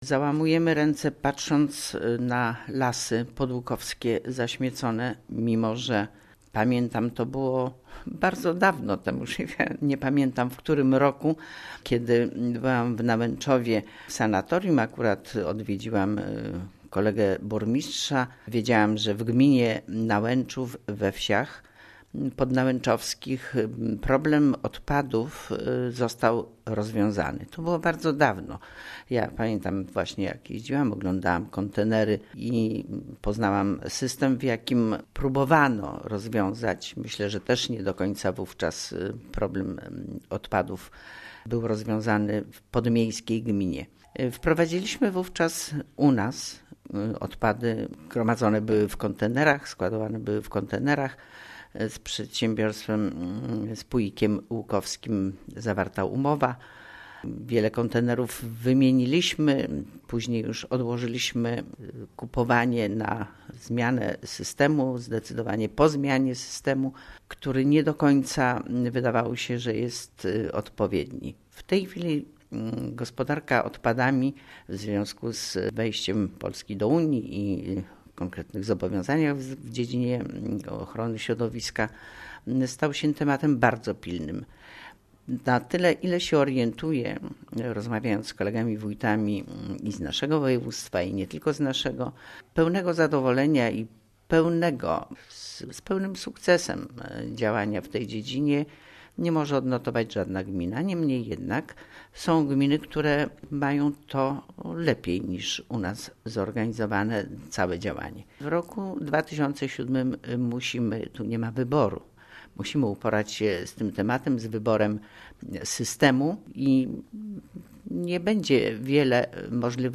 W�jt Gminy �uk�w Kazimiera Go�awska